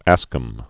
(ăskəm), Roger 1515-1568.